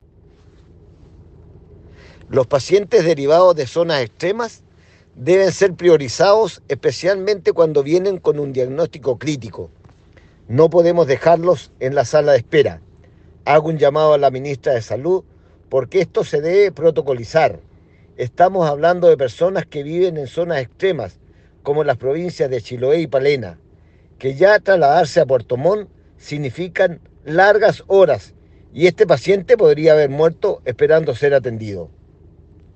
Una dura crítica y un llamado al sentido común realizó el diputado por el Distrito 26, Fernando Bórquez (Ind.-UDI), en sesión de la Cámara de Diputadas y Diputados realizada este martes, producto de una postergada y lenta atención de pacientes derivados del hospital de Chaitén.